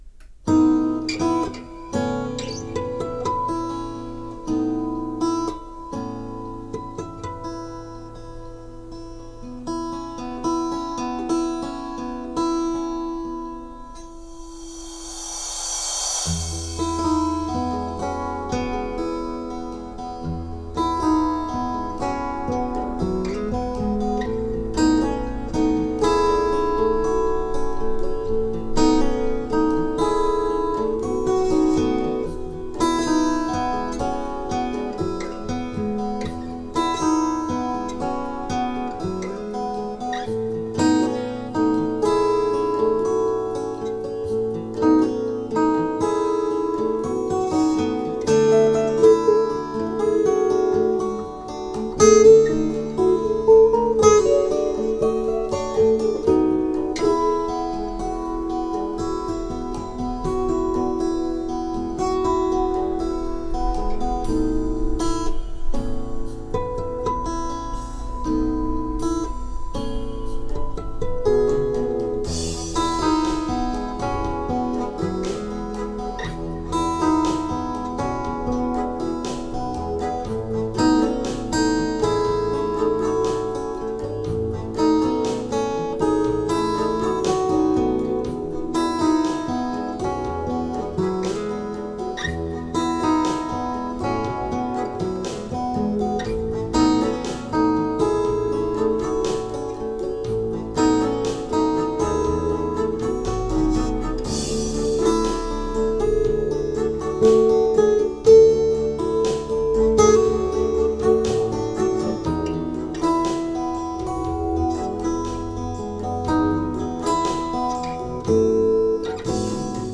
無指向性コンデンサーマイクを使用しました。
音楽はホームページに使用する都合上、著作権フリーCDを使用しました。
ラジカセからの距離は約50cmにセットします。
CS-80の音量ボリュームは、3の位置です。
音の変化を実際に聴けるように録音しました。
Fトーンコントロールのバスとトレブルはセンター、ラウドネスはオフ、安定化電源装置を接続。